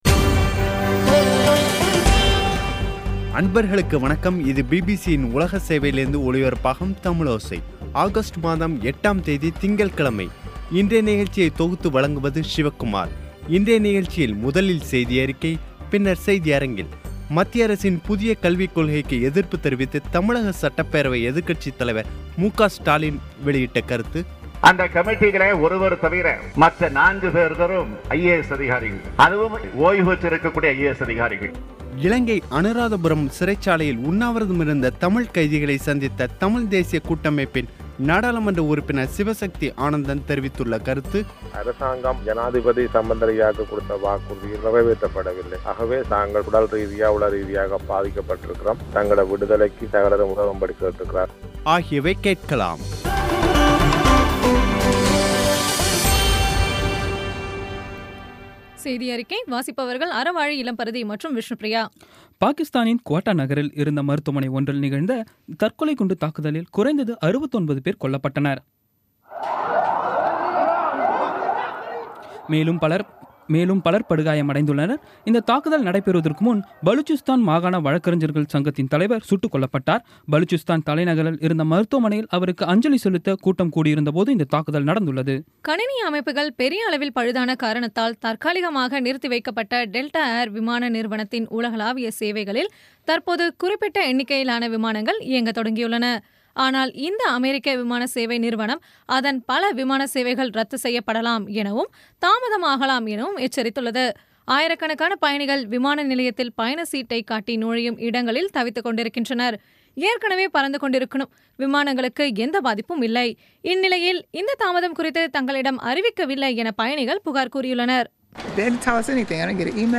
இன்றைய நிகழ்ச்சியில் முதலில் செய்தியறிக்கை, பின்னர் செய்தியரங்கில்